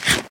eat1.ogg